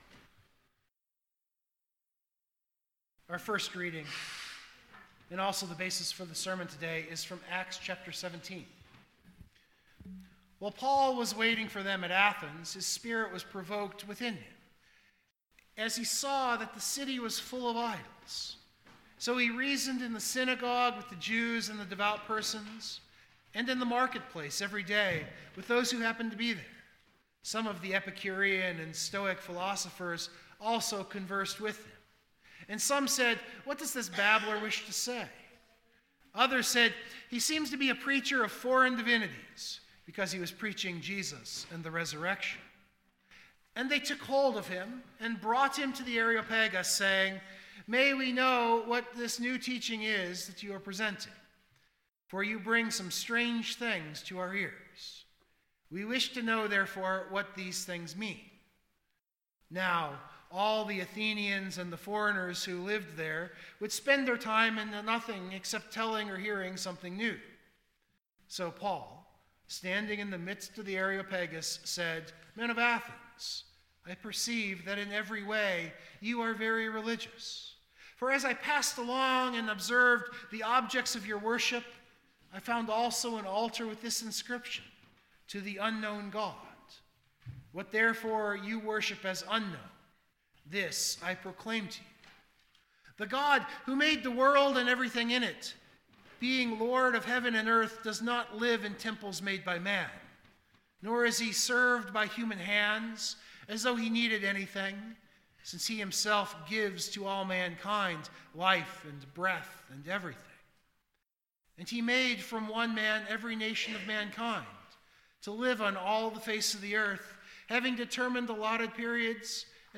Worship Note: I’ve left in our Hymn of the Day LSB 832 , Jesus Shall Reign.